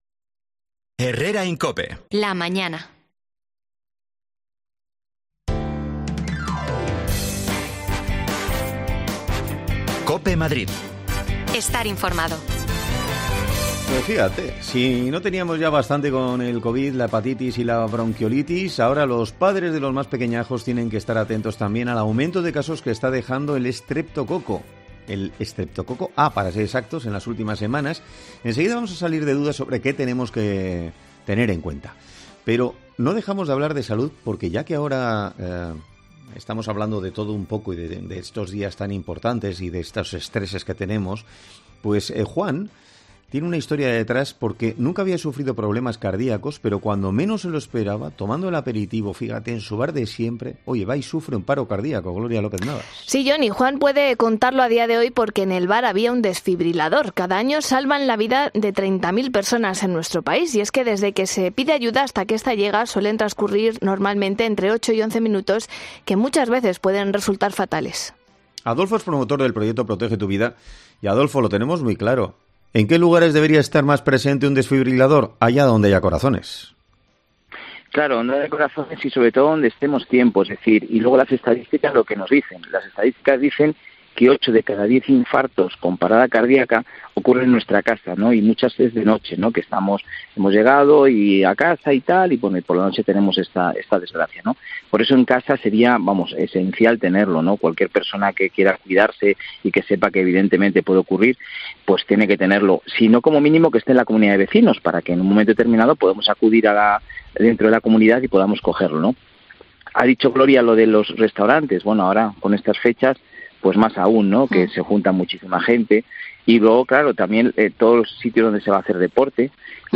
Dos menores han fallecido y 14 permanecen ingresados en la Comunidad de Madrid por culpa del estreptococo A. Hablamos con una doctora para saber si hay que preocuparse.